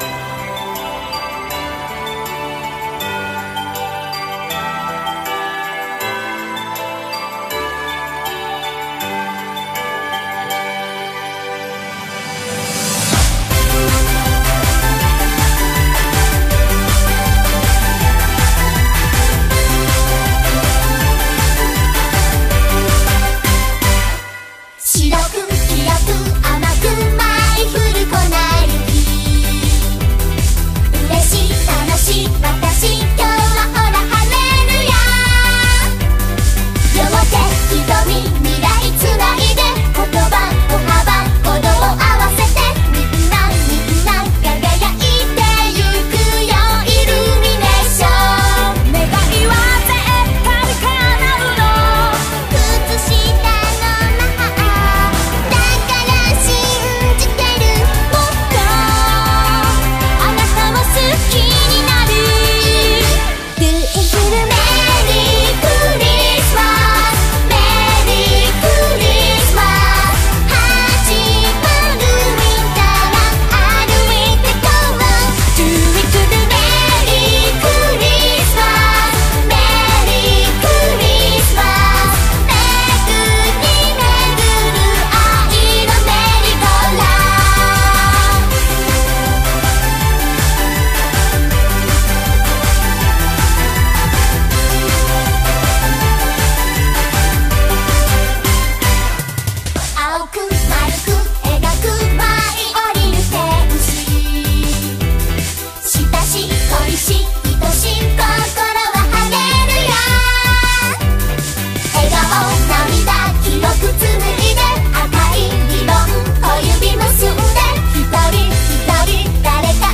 BPM80-160